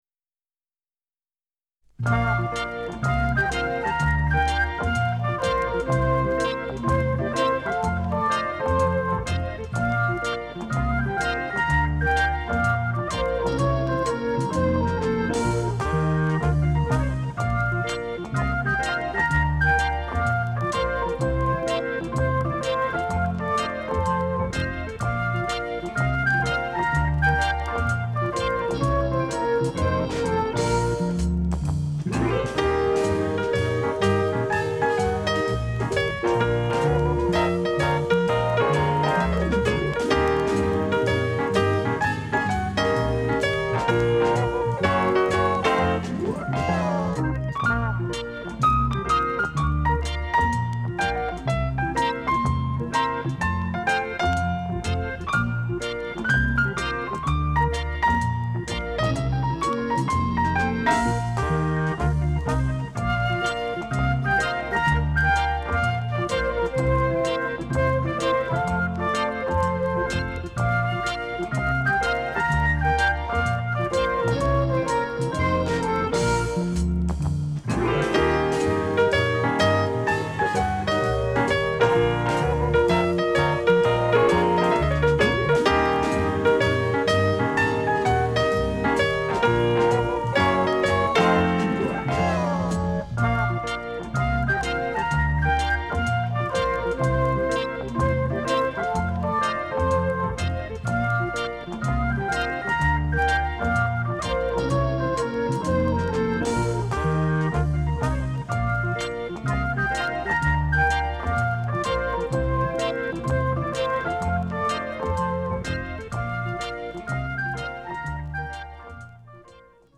Запись, которая поднимает настроение.